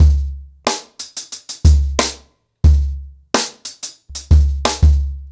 CornerBoy-90BPM.27.wav